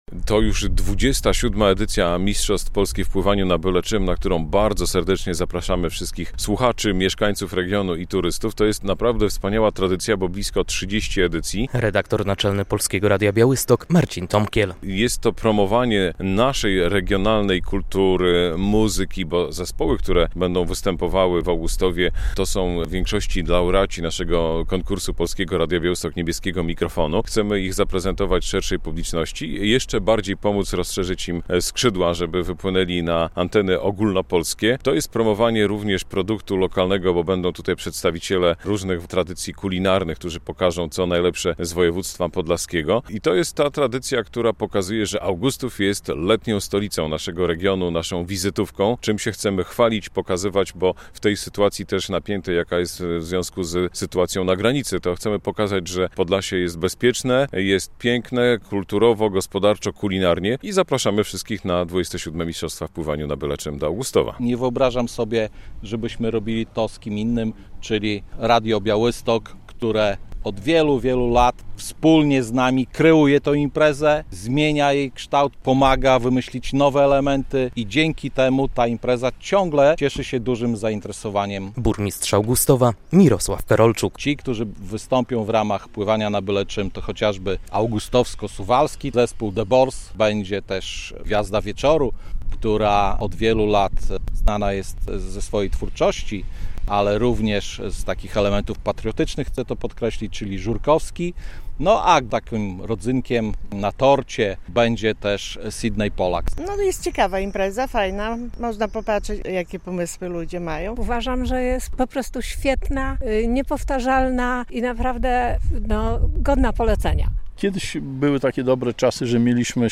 Podczas środowej konferencji na Błoniach nad rzeką Nettą do udziału w tej wyjątkowej imprezie zachęcali organizatorzy i partnerzy wydarzenia.
Konferencja przed XXVII Mistrzostwami Polski na Byle Czym w Augustowie - relacja